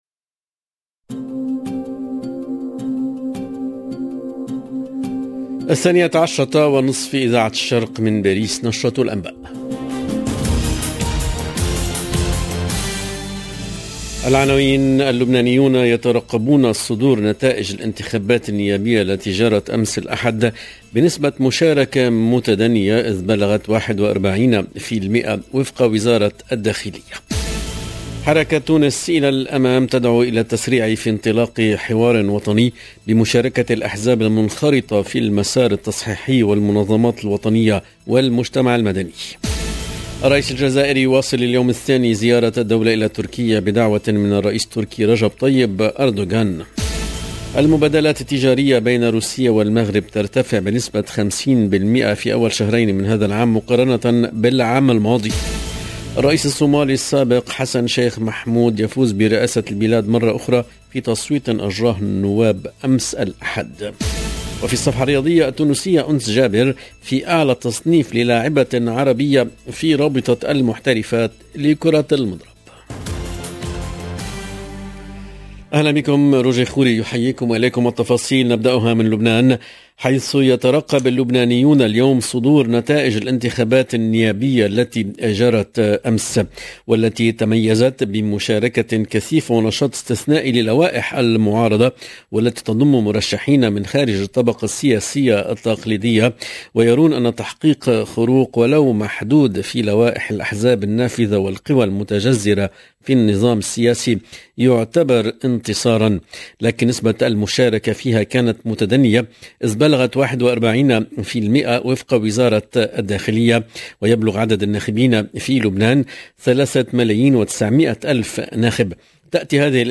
LE JOURNAL DE LA MI-JOURNEE EN LANGUE ARABE DU 16/05/22